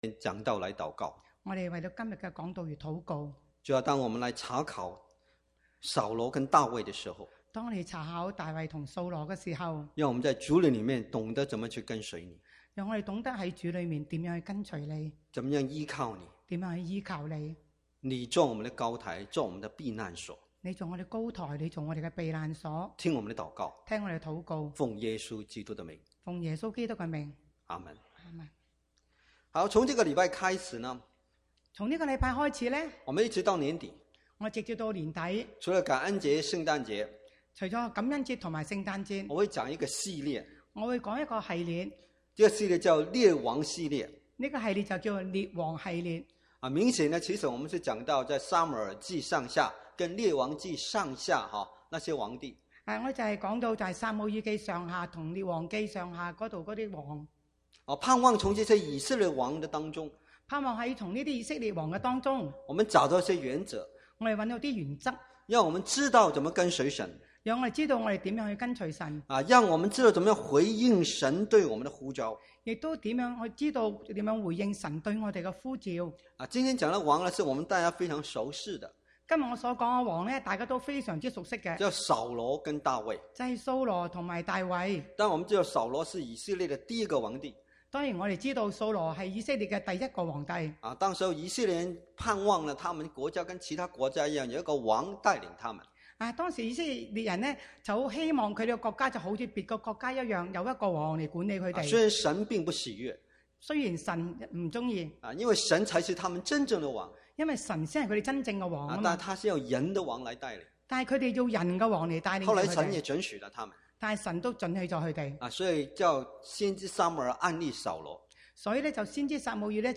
Combined Service
From Series: "Chinese Sermons"